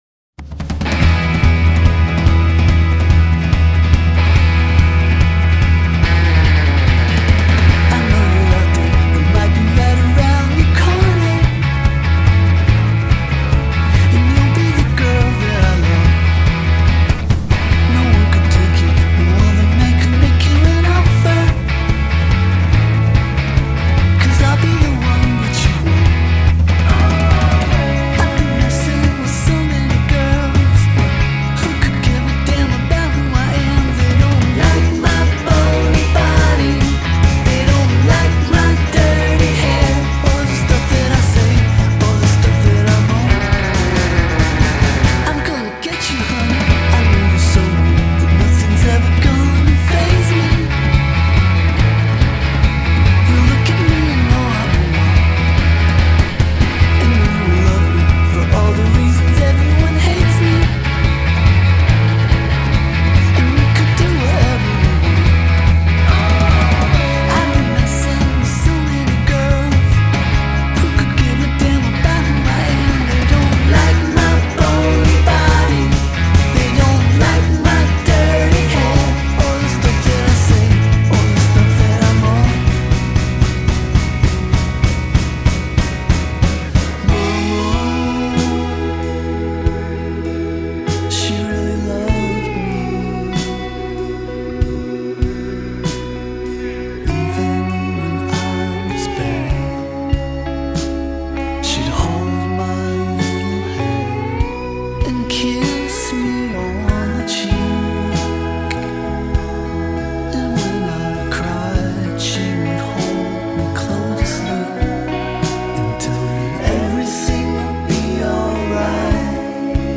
is the tastiest ear candy of the lot.